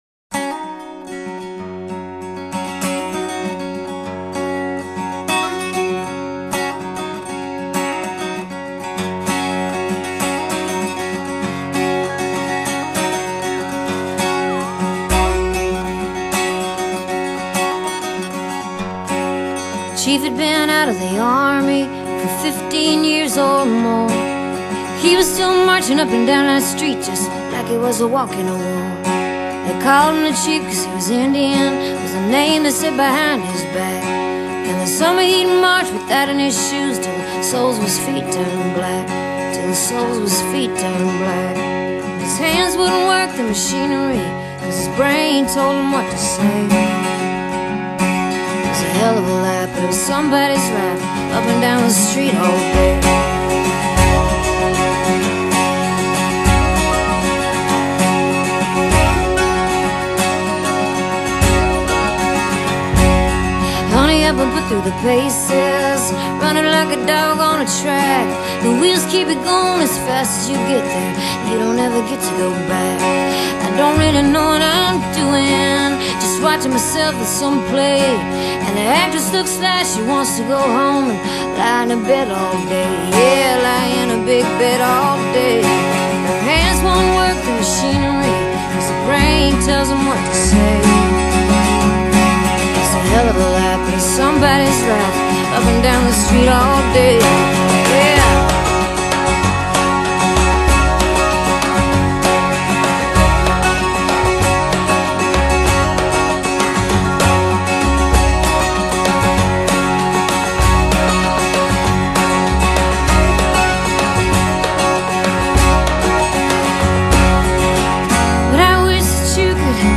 音樂類型：Folk  Rock